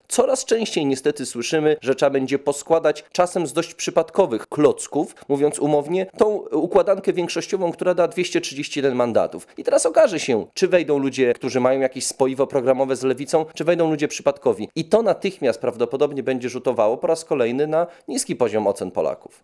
politolog.mp3